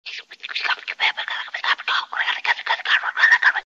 Звуки мультяшного голоса
Что-то тихо шепчет